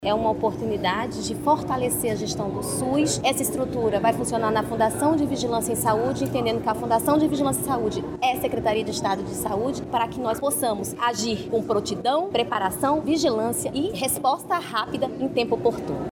Tatyana Amorim, diretora-presidente da Fundação de Vigilância em Saúde do Amazonas – FVS, destaca que, quando for implantado, o Centro de Inteligência Estratégica vai funcionar na sede da FVS.